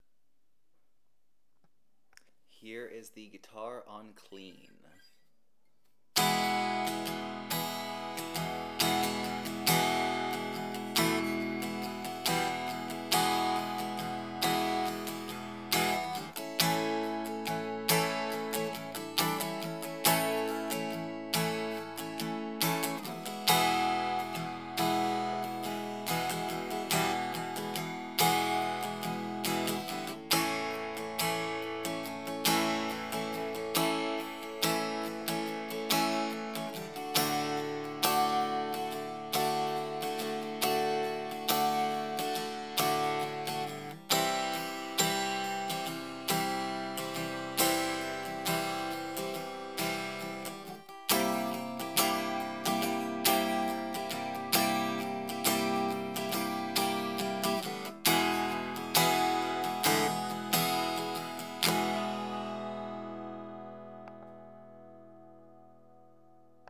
It has a ton of clarity from high to low and you get a lot of definition in the sound, but it has a much more sterile sound than a wood guitar would.
Here are some audio samples of how the guitar sounds acoustically. Both with and without effects. This was recorded using my Olympus LS-100 stereo recorder.
(Clean)